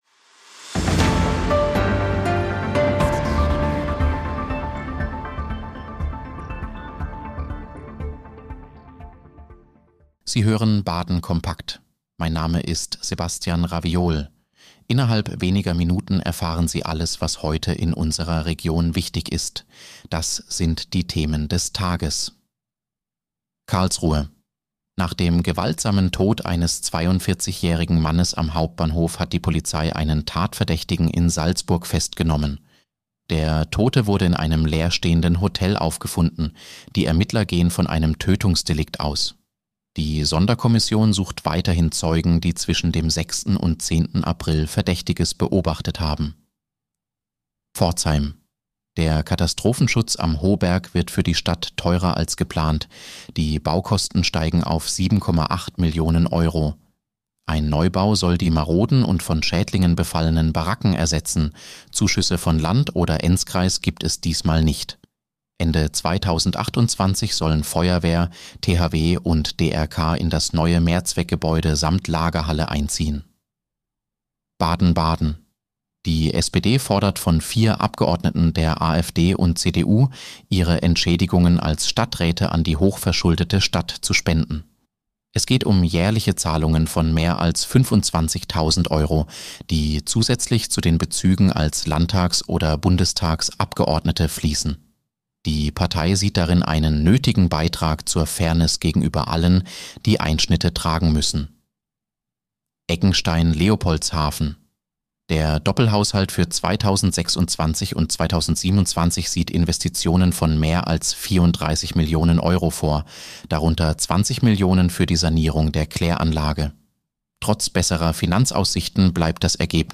Nachrichtenüberblick: Festnahme nach Tötungsdelikt am Karlsruher Hauptbahnhof